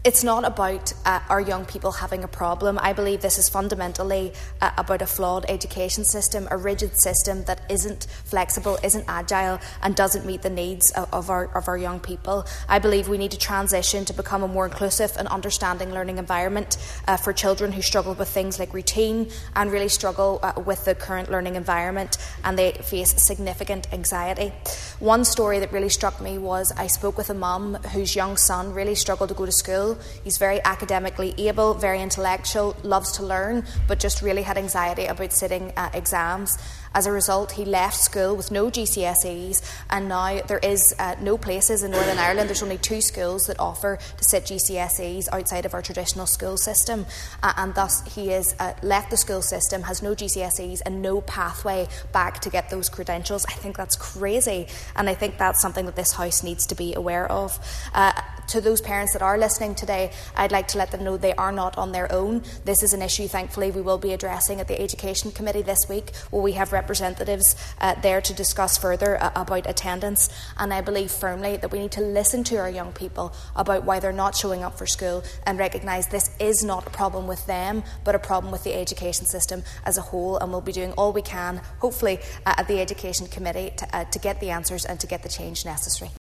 An East Derry MLA has told the Stormont Assembly that an increase in the number of children refusing to go to school points to a serious problem within the education system that needs to be addressed.